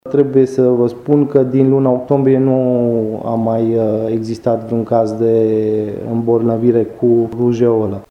Prefectul judeţului Covasna, Sebastian Cucu: